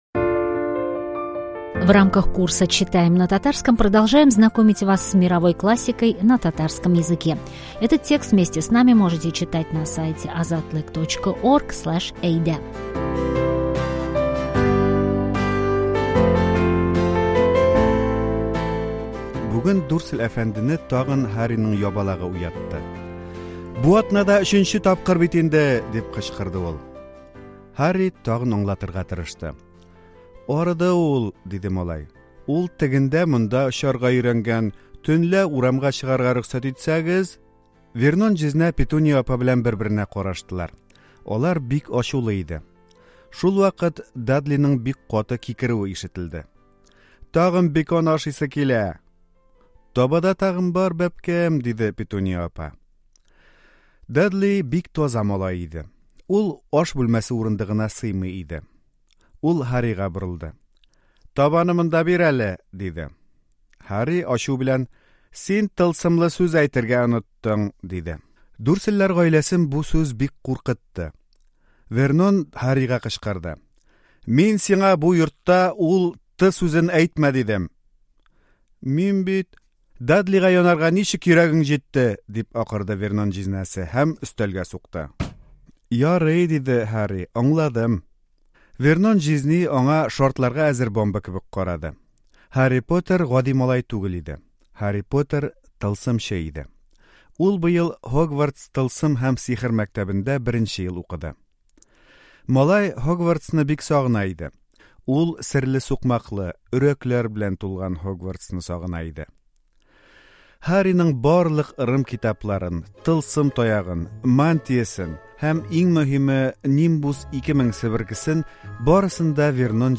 Сегодня мы читаем мировой бестселлер последних десятилетий – вторую часть книг о Гарри Поттере, Һарри Поттер һәм серләр бүлмәсе (Гарри Поттер и тайная комната).